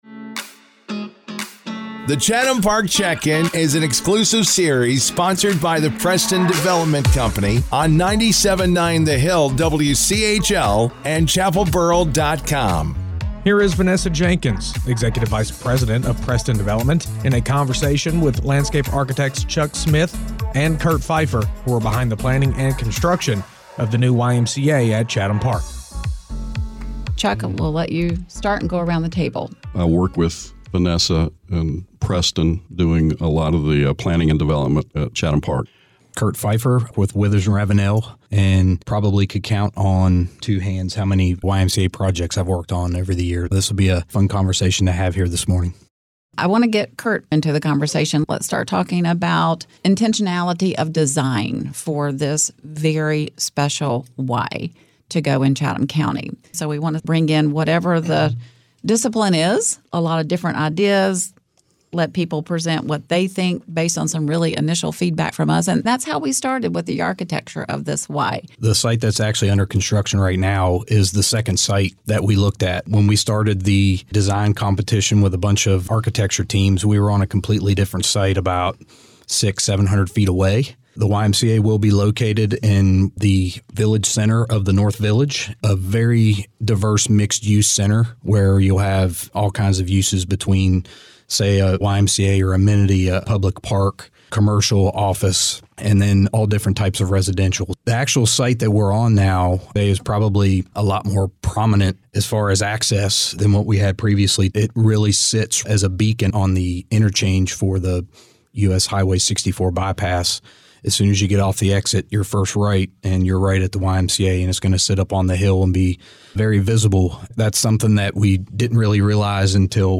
The Chatham Park Check-In continues with the next series of interviews